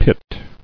[pit]